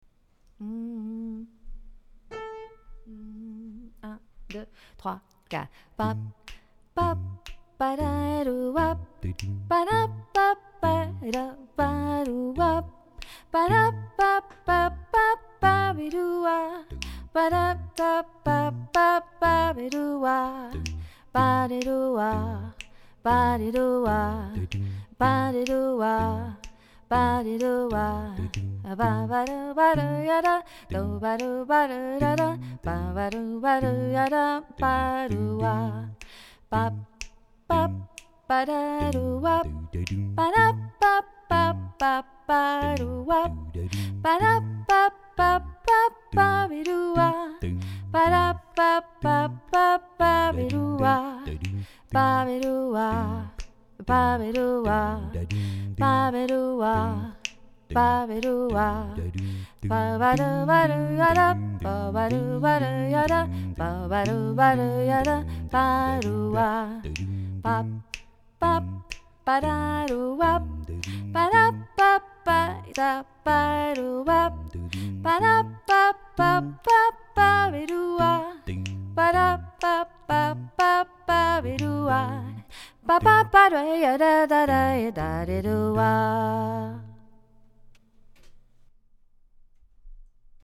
Chorale adultes
La première version est la version finale, c'est-à-dire ce que donnera le morceau, une fois que nous l'aurons tous appris. les autres versions sont les voix séparées qui vous concernent : la voix que vous devez apprendre est mise en avant, et vous entendrez le reste en fond sonore.
- Voix solo :